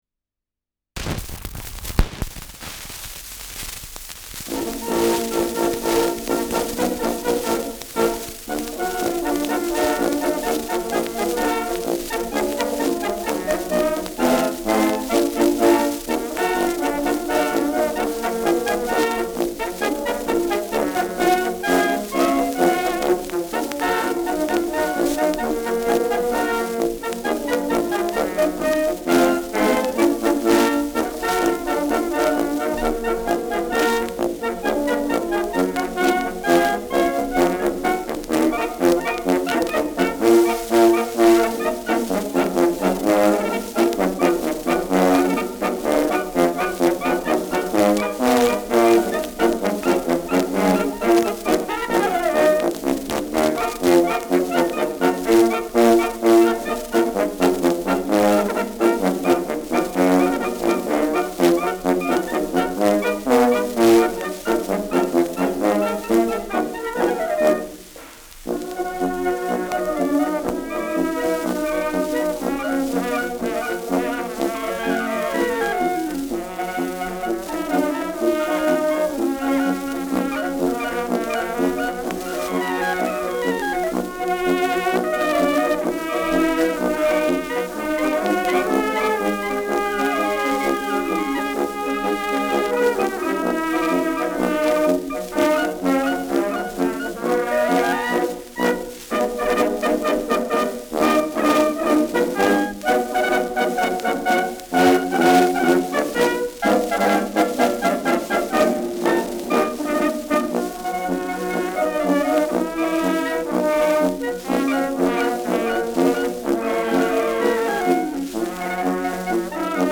Schellackplatte
Starkes Grundrauschen : Durchgehend leichtes Knacken